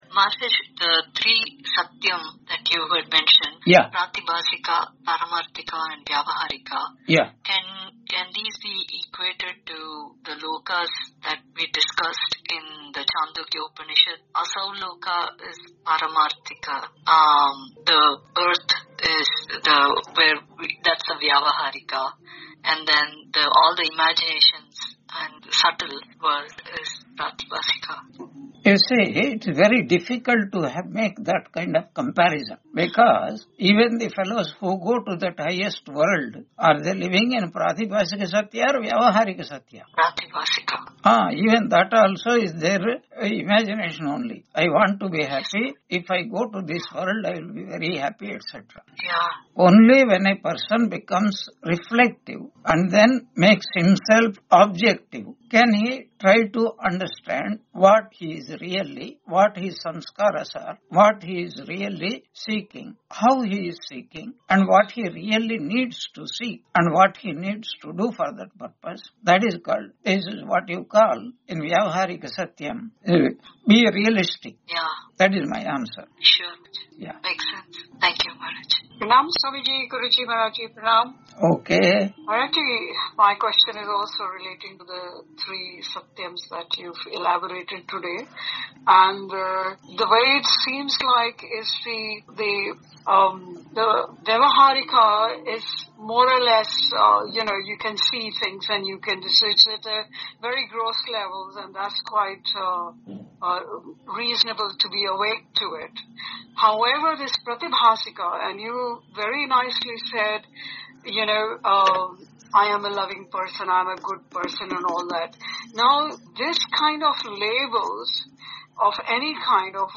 Taittiriya Upanishad Lecture 85 Ch2 6.3 on 31 December 2025 Q&A - Wiki Vedanta